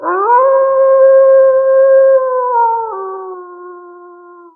wolf2.wav